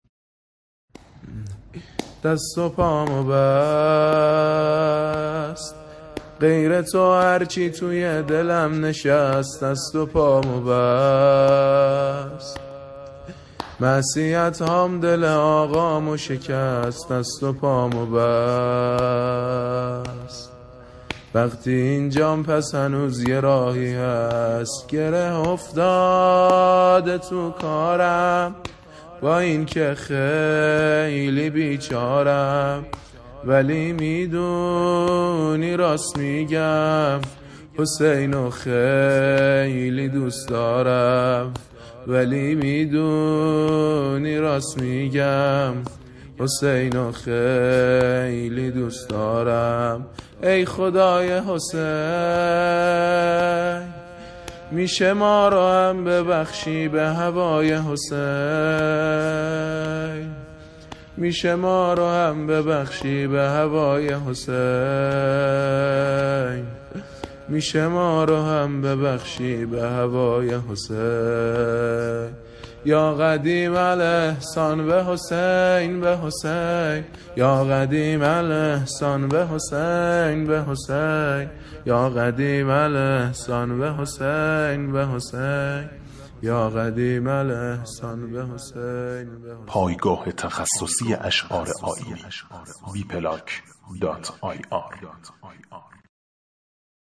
مناجات عاشقانه